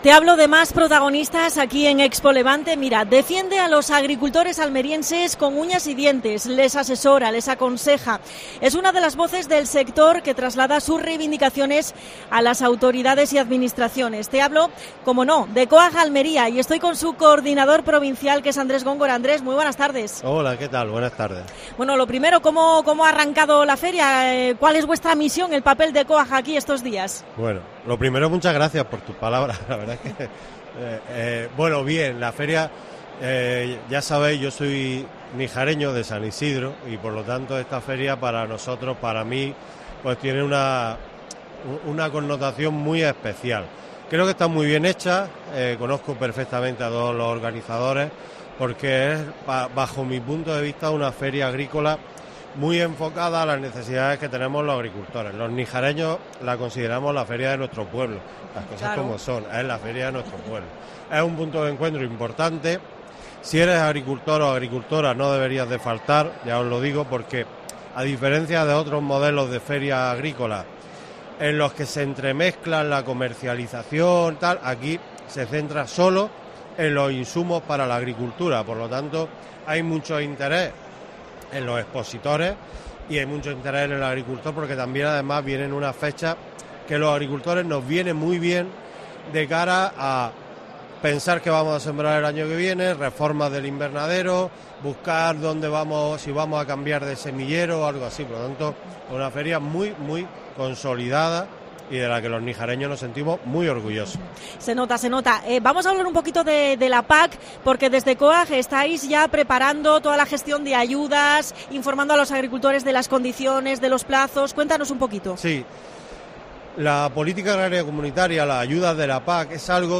en el especial de COPE Almería en ExpoLevante.